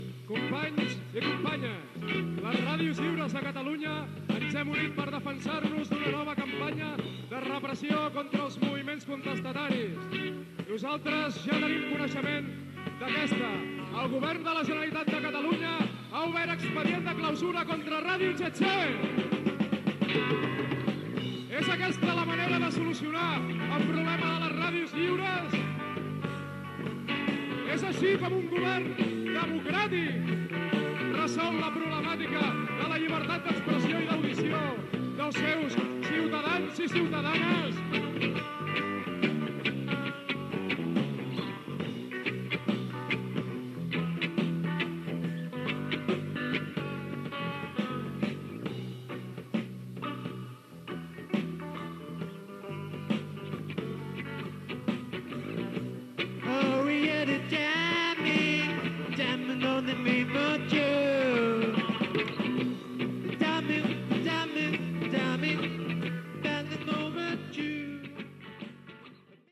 Extret del casset "Ràdio tse tse Llibertat d'Audició".